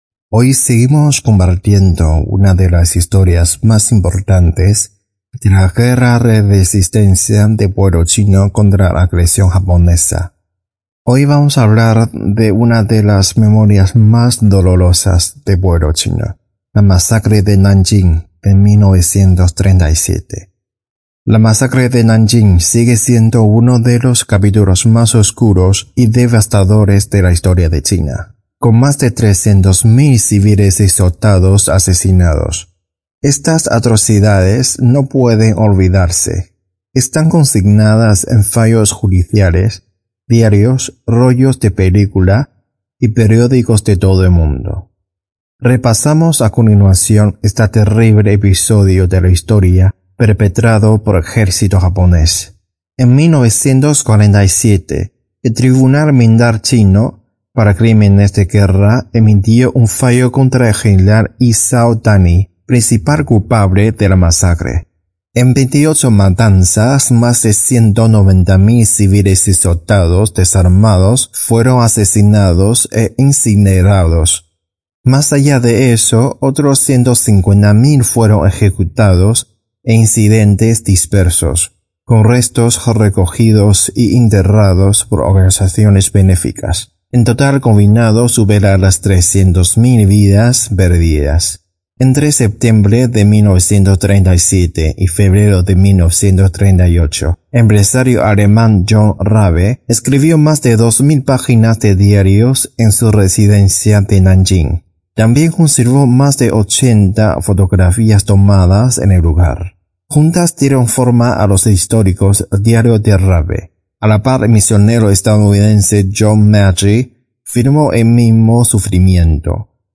Estos 4 episodios fueron emitidos en nuestro programa radial, Clave China, los días 17, 24 y 31 de Agosto y 7 de septiembre del 2025